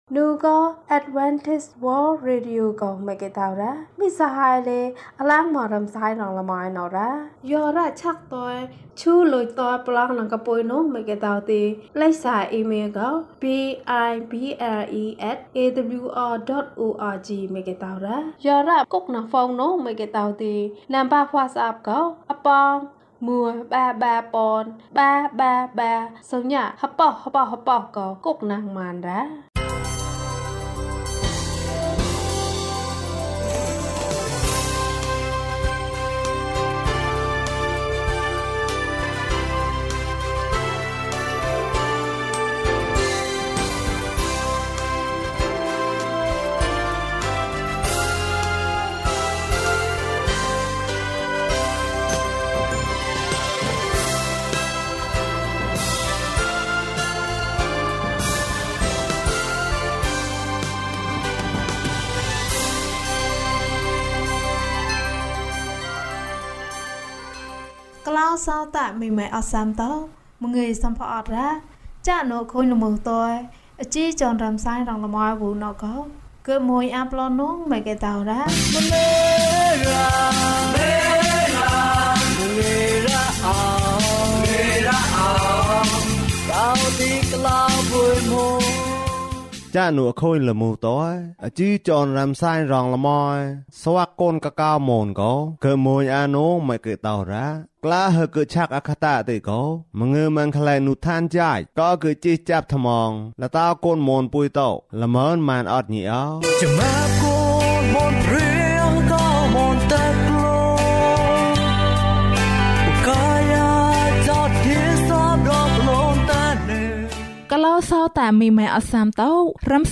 ယေရှုငါ့ကိုခေါ်ပါ။ ကျန်းမာခြင်းအကြောင်းအရာ။ ဓမ္မသီချင်း။ တရားဒေသနာ။